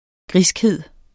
Udtale [ ˈgʁisgˌheðˀ ]